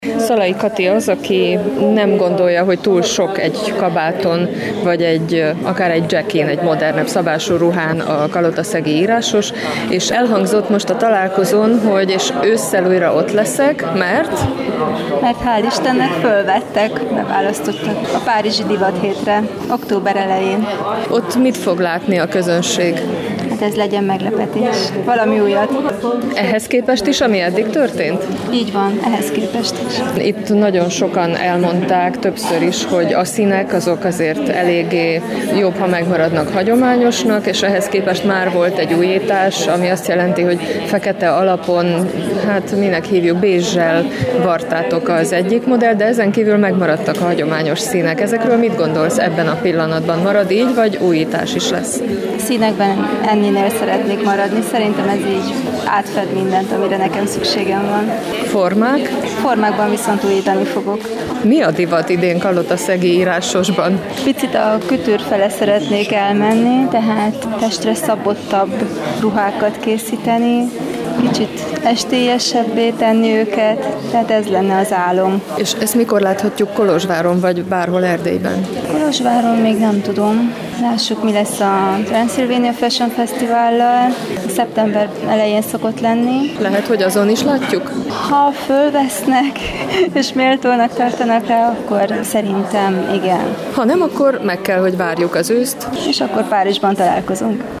Mintákról, motívumokról, a nagyírásos történetéről is beszéltek a témában szervezett kolozsvári találkozón.